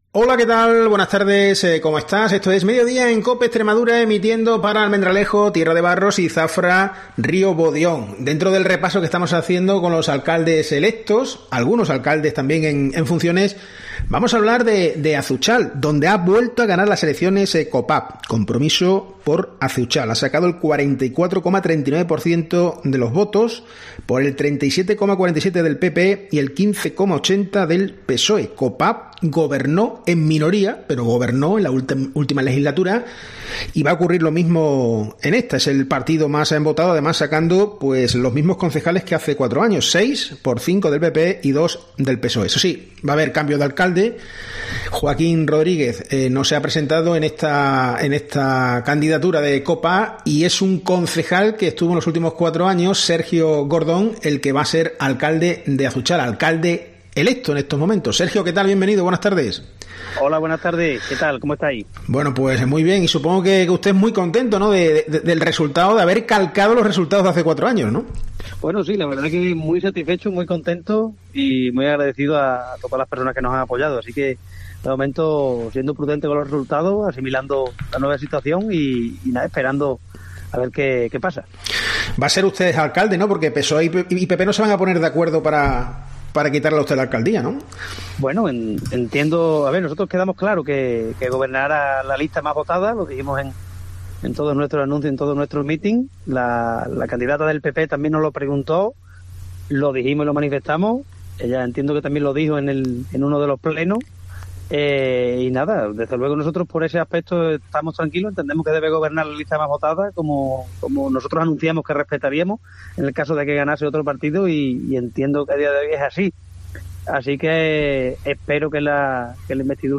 En Cope hemos hablado con el alcalde electo.